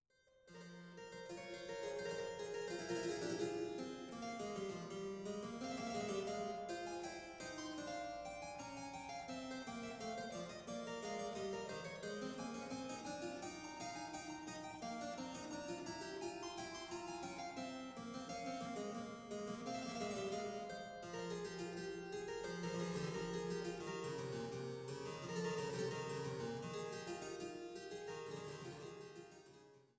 Schlosskirche Altenburg
Cembalo